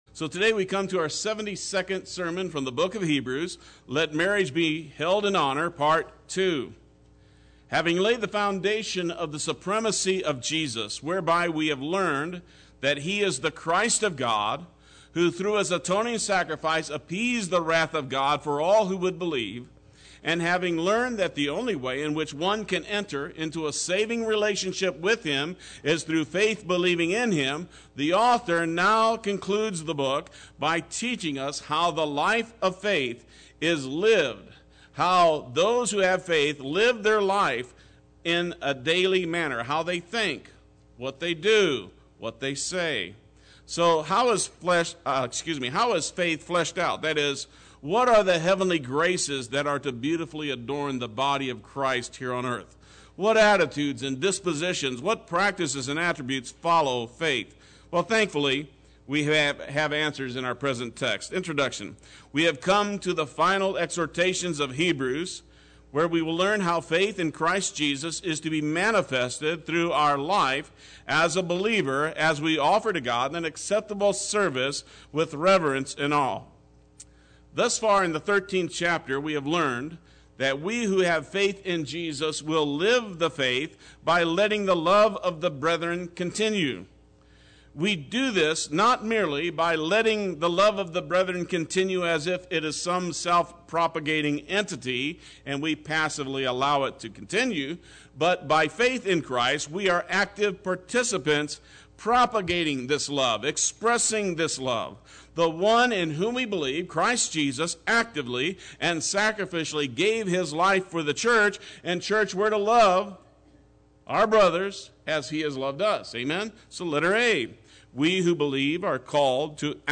Play Sermon Get HCF Teaching Automatically.
Part 2 Sunday Worship